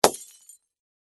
Sounds of lamps and luminaires